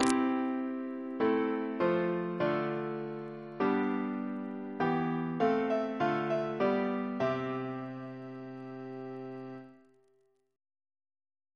CCP: Chant sampler
Single chant in C Composer: George S. Talbot (1875-1918) Reference psalters: H1982: S40; RSCM: 211